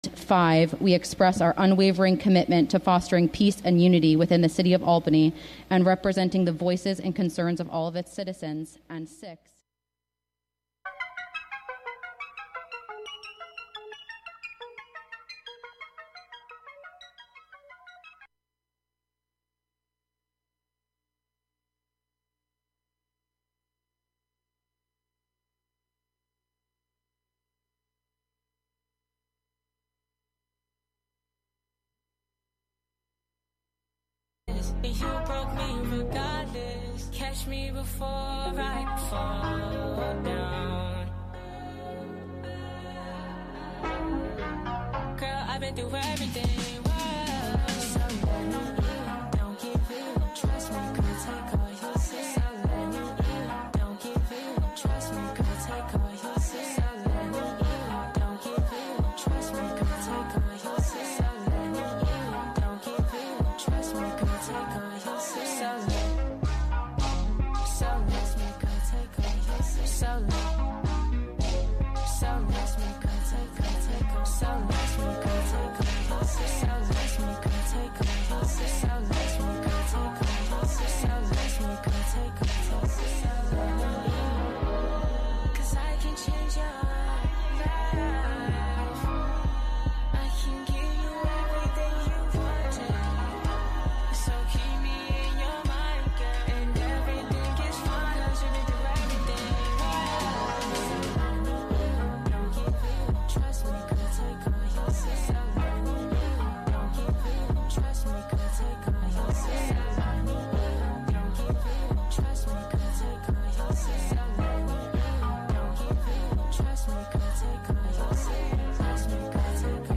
Show includes local WGXC news at beginning, and midway through.